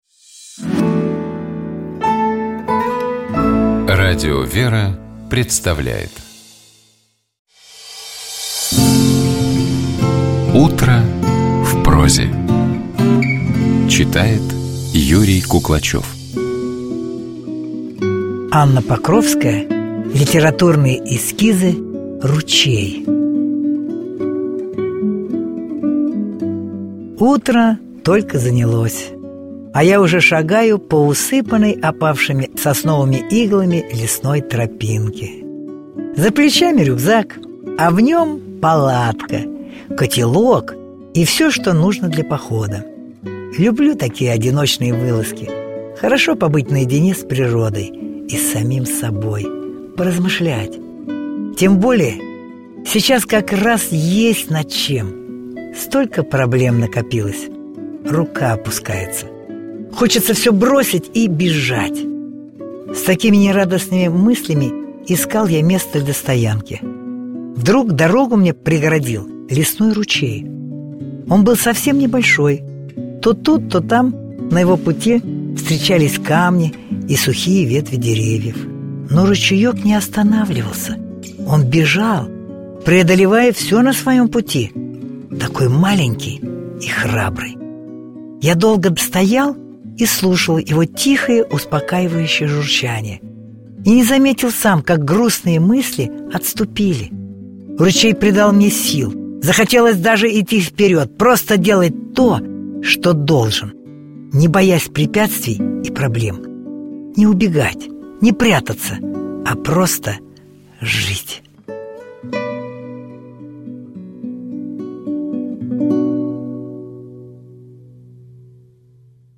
Текст Анны Покровской читает Юрий Куклачев.